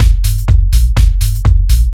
• fat bouncy techno kick.wav
fat_bouncy_techno_kick_DOc.wav